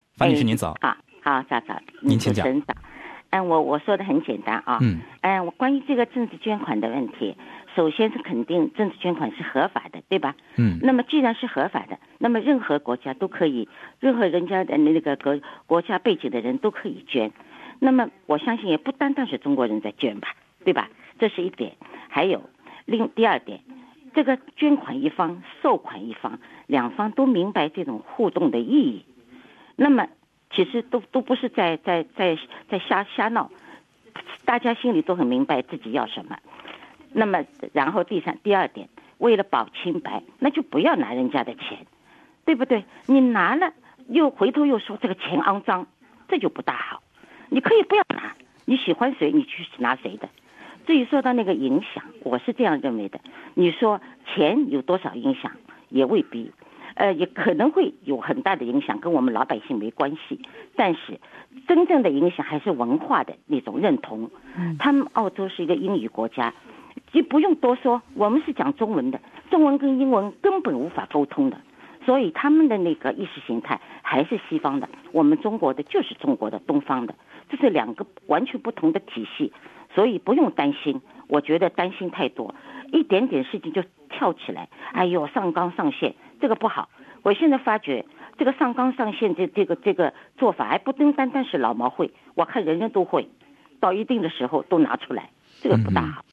本台正在行动节目的一些听众在热线中表达了自己的观点，认为动辄上纲上线不好。